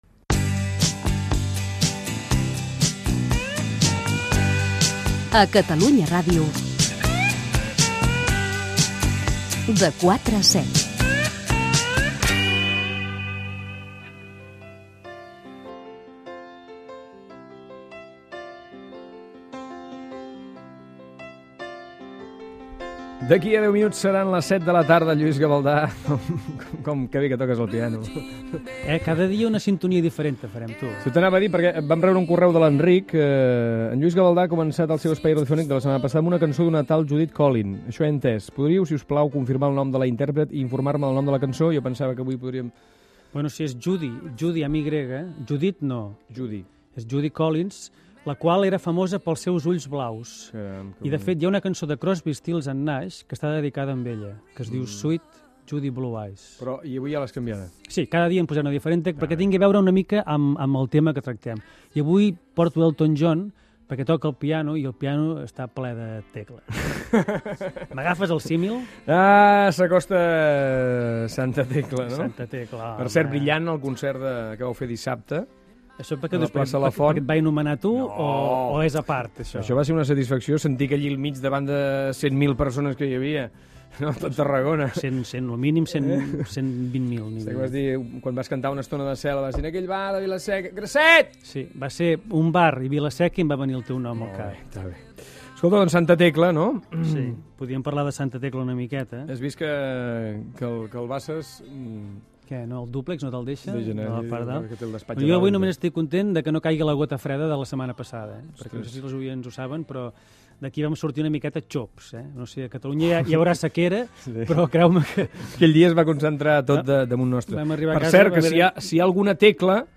Indicatiu del programa, espai del músic Lluís Gavaldà qui parla de la Festa de Santa Tecla de Tarragona. tema d'Elton John, equip del programa i comiat
Entreteniment